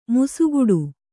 ♪ musuguḍu